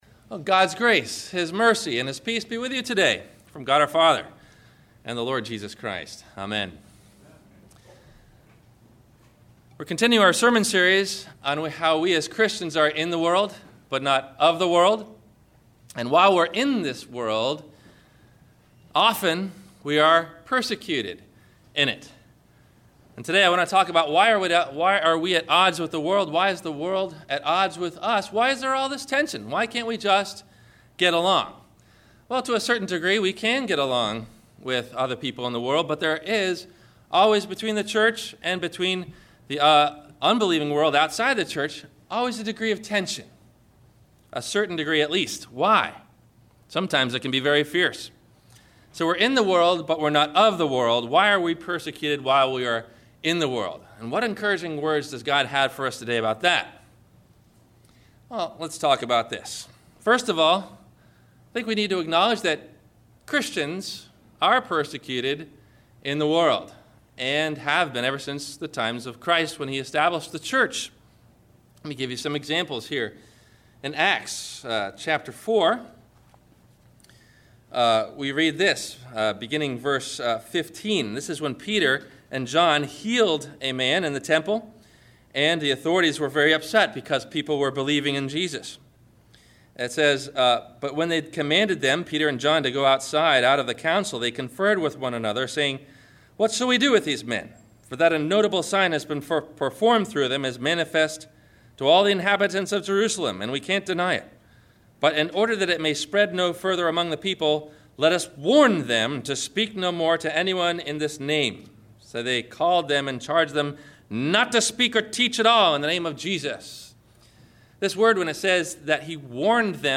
The Persecution of the Christian – Sermon – October 23 2011